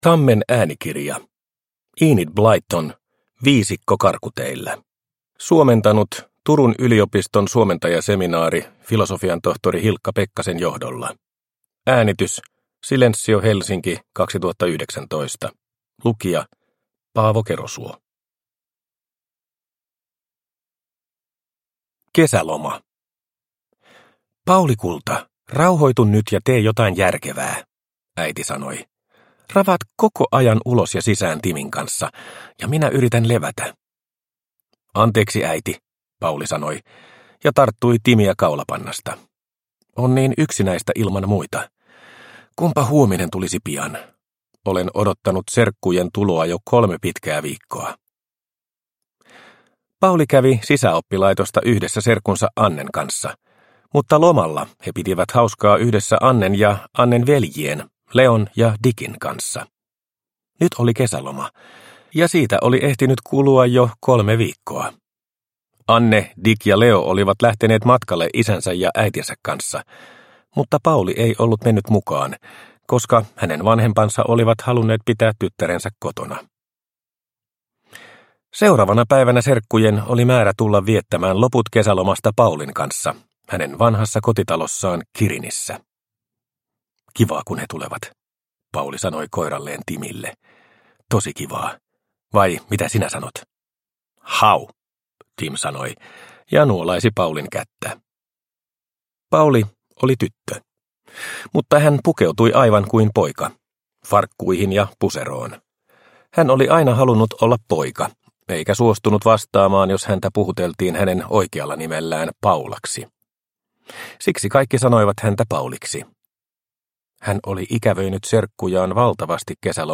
Viisikko karkuteillä – Ljudbok – Laddas ner